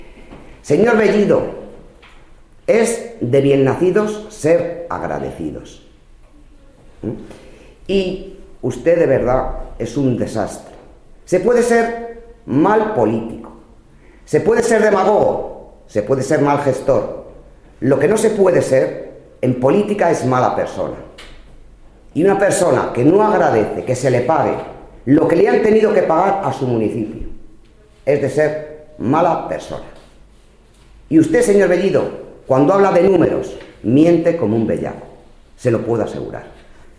Así se ha manifestado la diputada regional María José Agudo en el transcurso de una rueda de prensa que ha ofrecido hoy a los medios de comunicación. Agudo ha atacado la actitud del secretario general del PSOE en Guadalajara y alcalde de Azuqueca.